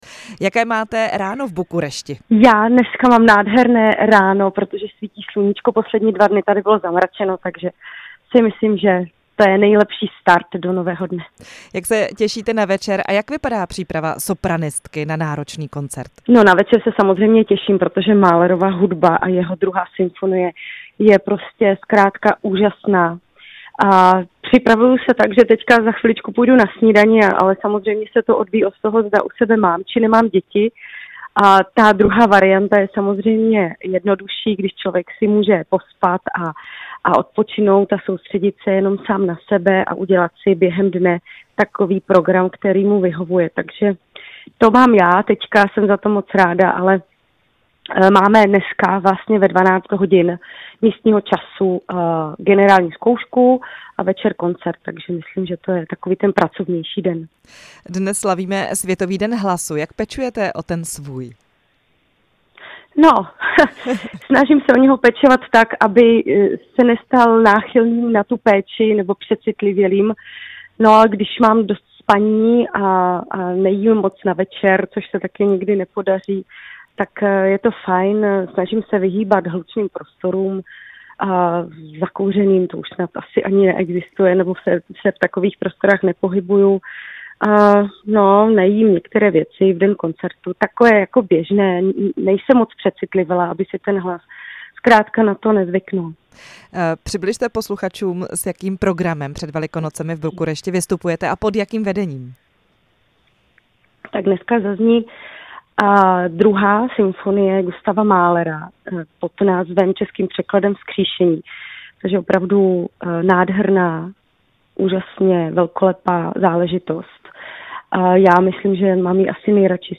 Ve vysílání Rádia Prostor byla hostem sopranistka Kateřina Kněžíková, která se od pondělí pilně připravuje v rumunské Bukurešti na své jarní mahlerovské koncerty.
Rozhovor se sopranistkou Kateřinou Kněžíkovou